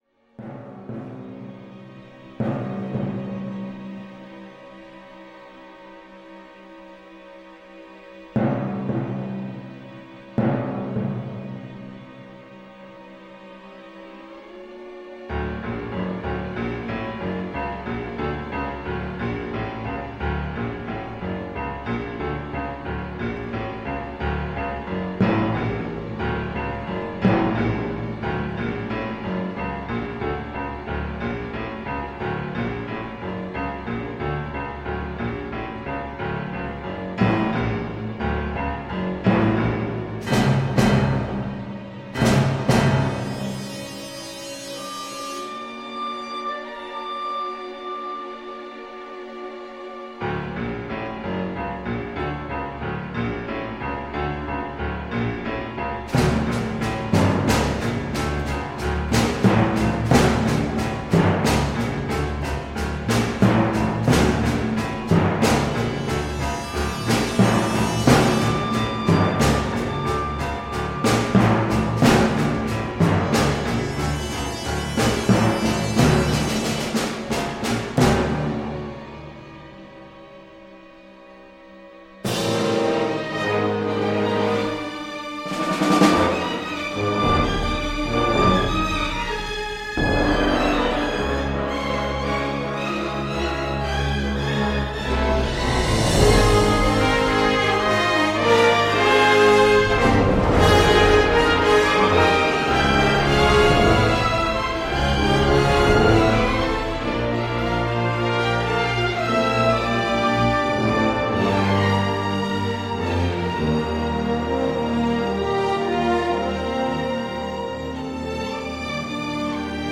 score martial et agressif